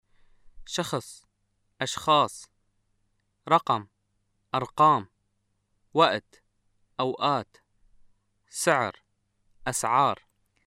シリアのアラビア語 文法 名詞の複数形：例文
[ʃaxS (ʔaʃxaaS), raqm (ʔarqaam), waʔt (ʔawʔaat), səʕr (ʔasʕaar)]